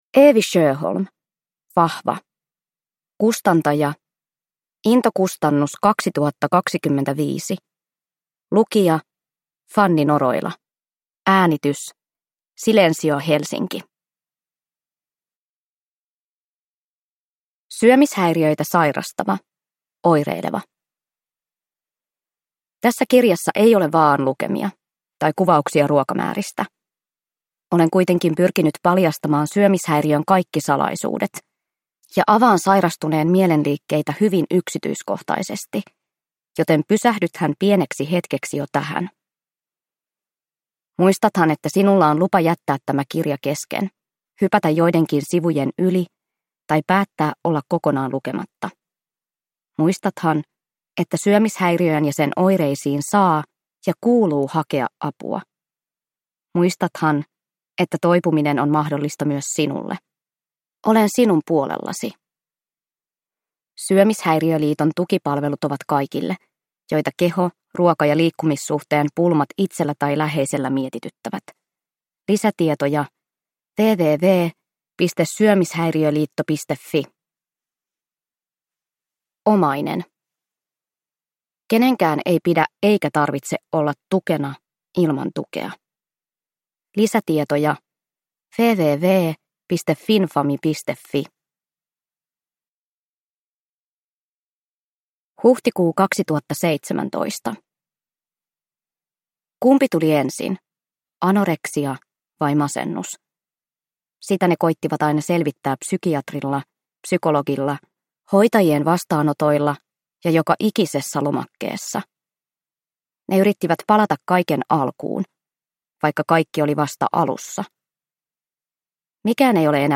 Vahva – Ljudbok